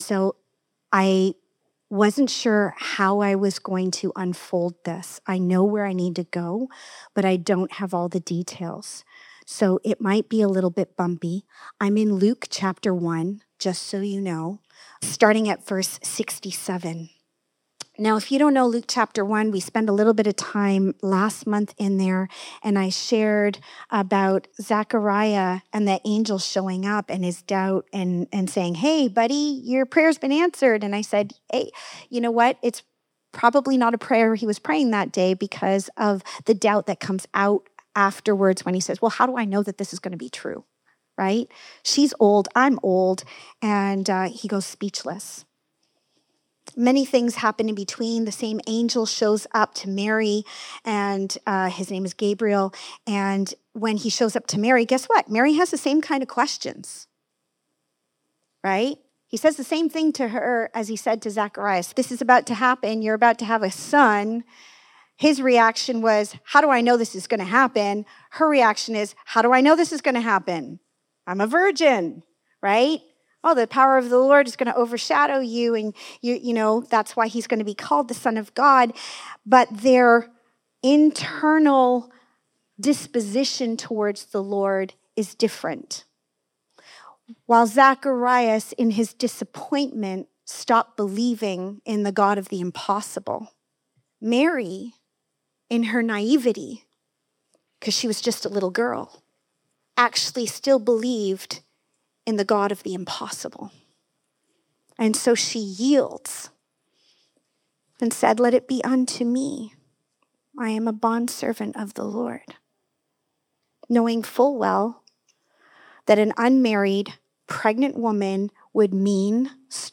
Luke 1:67 Service Type: Sunday Morning In Luke 1:67